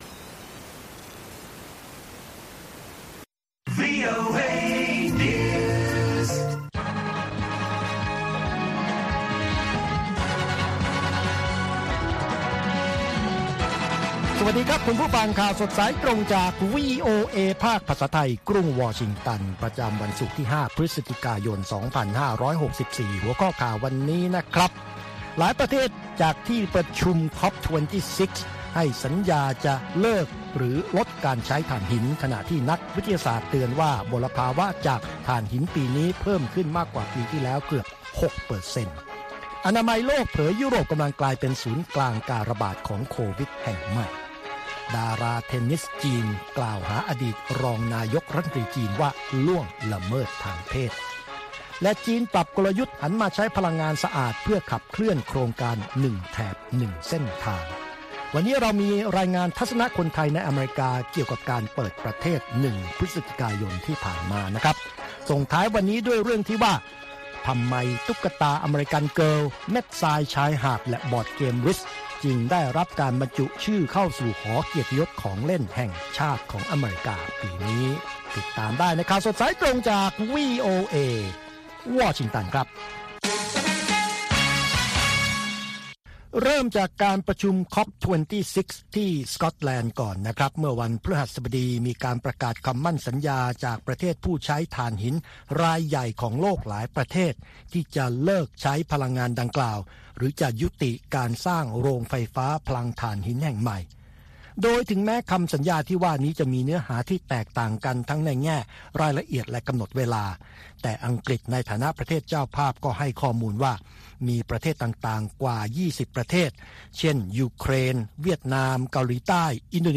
ข่าวสดสายตรงจากวีโอเอ ภาคภาษาไทย ประจำวันศุกร์ที่ 5 พฤศจิกายน 2564 ตามเวลาประเทศไทย